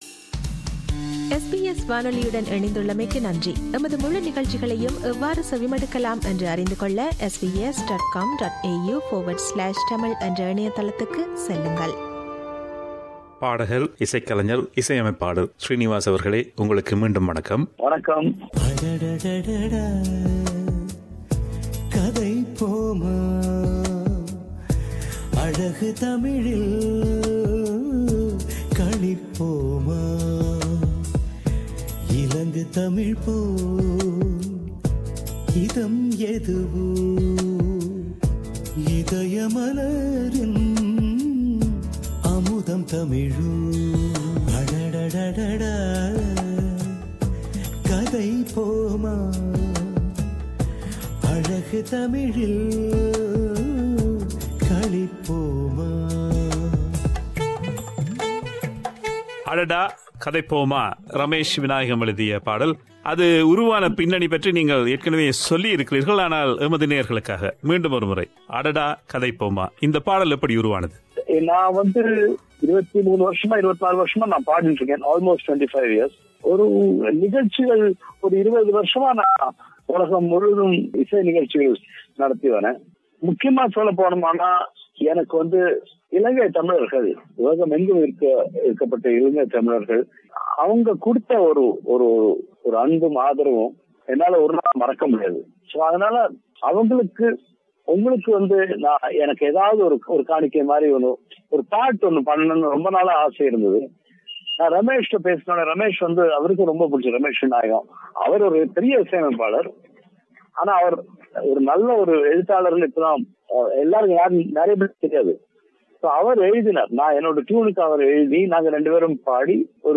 இரண்டு பாகங்களாக ஒலிபரப்பாகும் நேர்காணலின் முதல் பாகத்தில், பின்னணி பாடல்கள் பாட ஆரம்பித்த நாட்கள், அவரது இரசிகர்களுடனான உறவுகள் பற்றியும் ஒரு இசையமைப்பாளராகத் தனது அனுபவங்கள் குறித்தும் பேசுகிறார்.